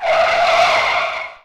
car_brake.ogg